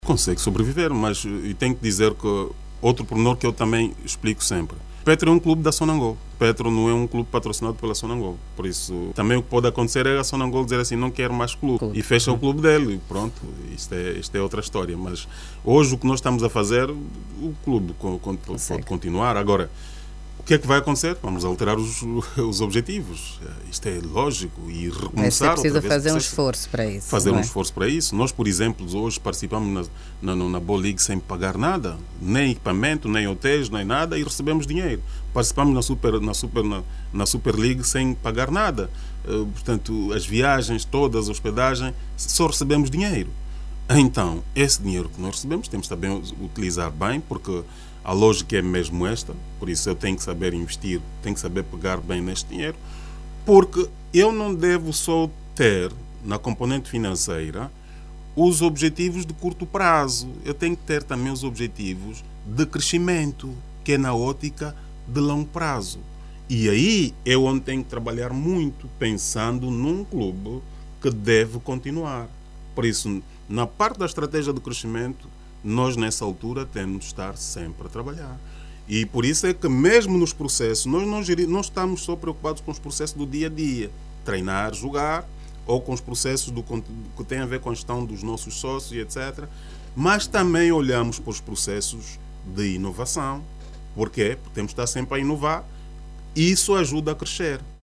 na grande entrevista do manhã informativa da RNA